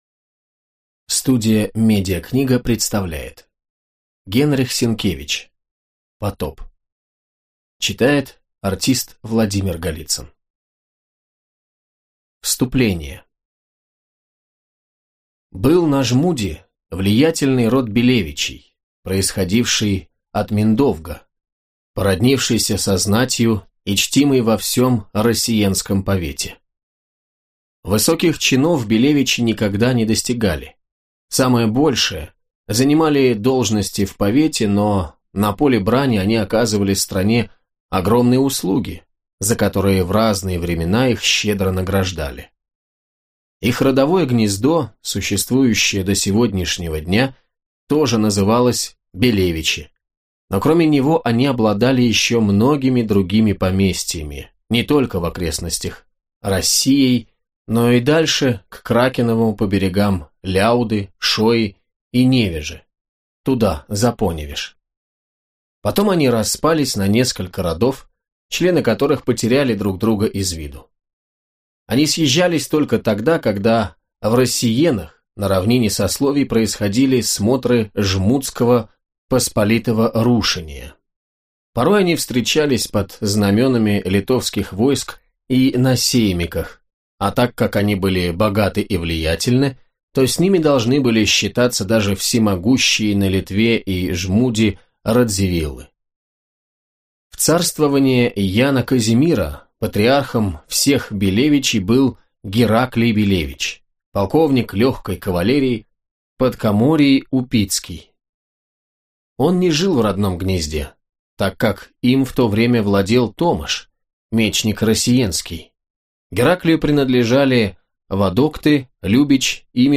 Аудиокнига Потоп - купить, скачать и слушать онлайн | КнигоПоиск
Аудиокнига «Потоп» в интернет-магазине КнигоПоиск ✅ Зарубежная литература в аудиоформате ✅ Скачать Потоп в mp3 или слушать онлайн